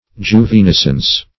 Search Result for " juvenescence" : Wordnet 3.0 NOUN (1) 1. the process of growing into a youth ; The Collaborative International Dictionary of English v.0.48: Juvenescence \Ju`ve*nes"cence\, n. A growing young.